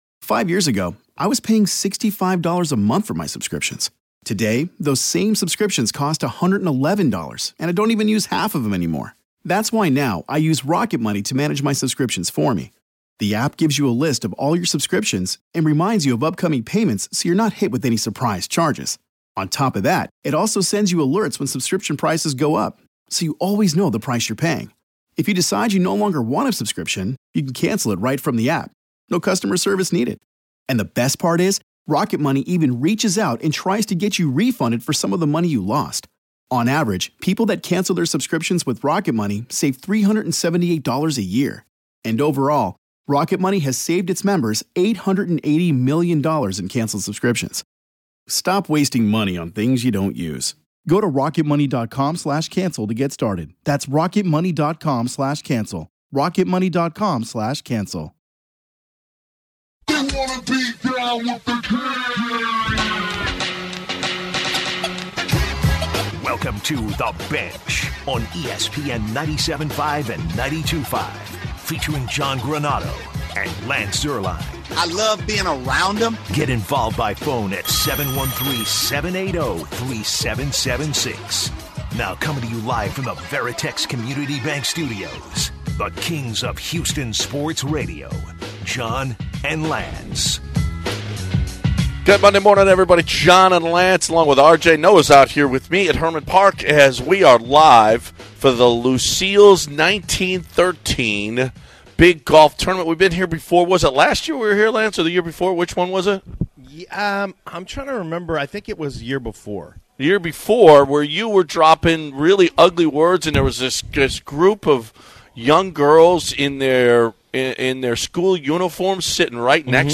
Starting today's show from Hermann Park Golf Course